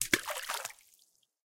splash lure.ogg